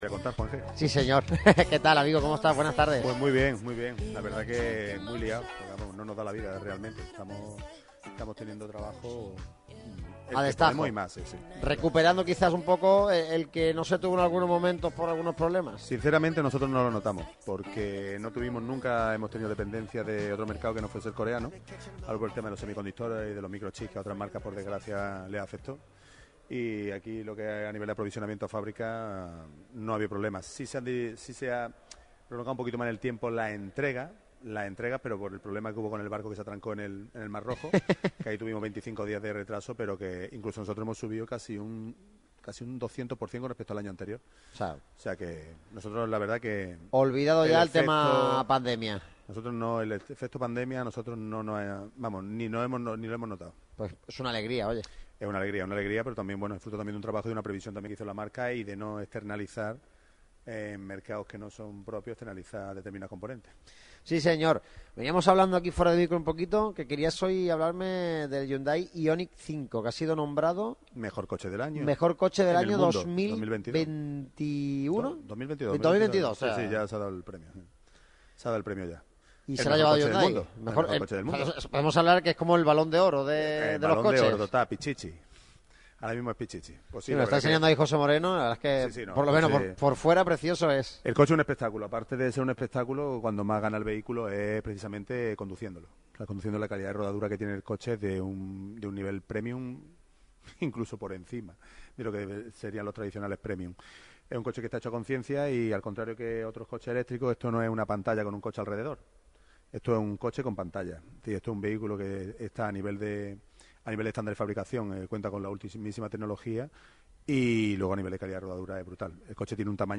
La radio del deporte se desplaza hasta Automóviles Nieto Hyundai para abordar los últimos movimientos de cara a la próxima planificación deportiva del Málaga CF. Fechas de pretemporada, reuniones con los jugadores, tertulias y debate.